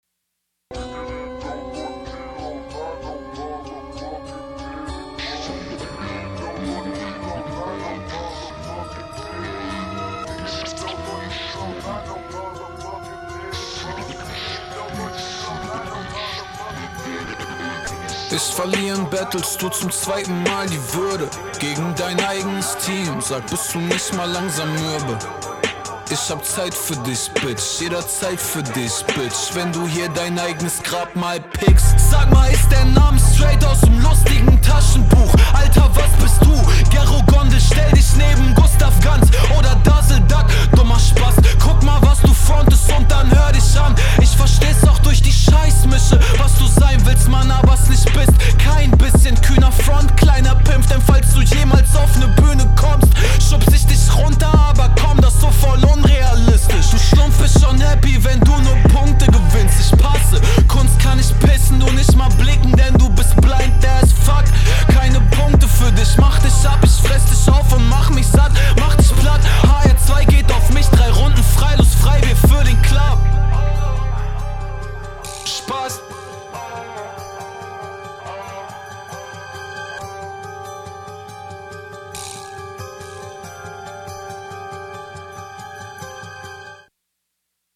Ai... ähm.. du bist nicht richtig "off-beat" sondern hast hier total merkwürdige Flows.